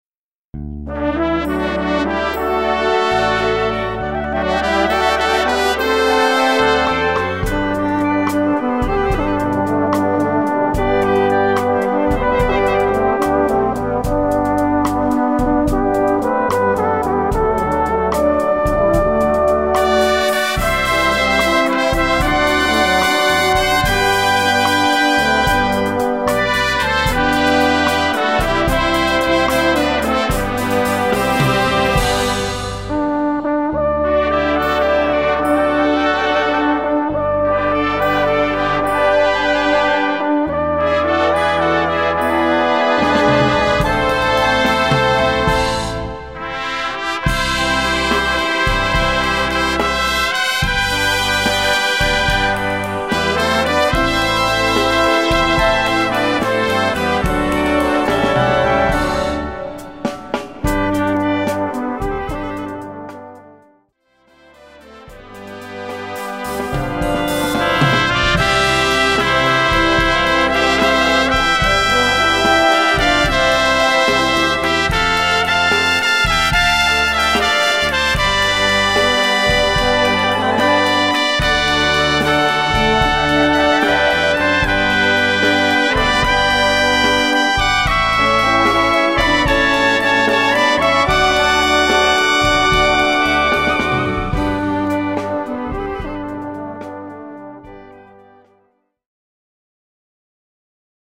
Gattung: Slow-Rock für Alphorn
Besetzung: Blasorchester